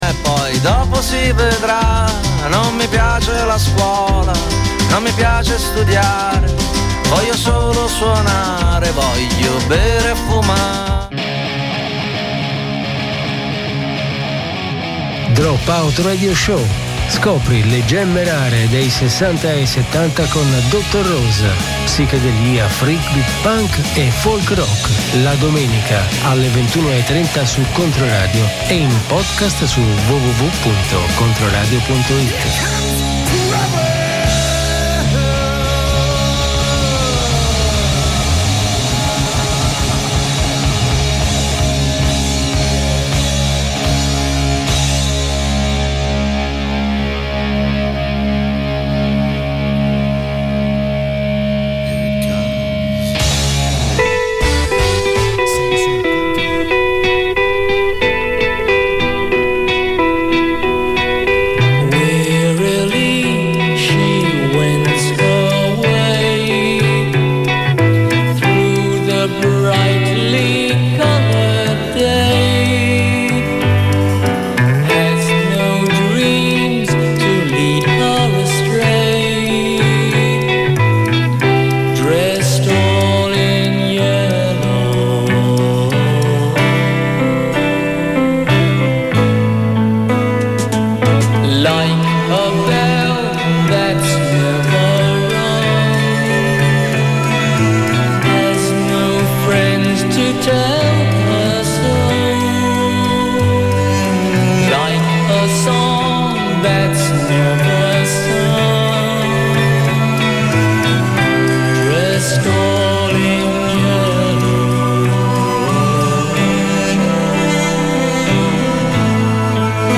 Scopri le gemme rare degli anni '60 e '70: psichedelia, freakbeat, punk e folk rock.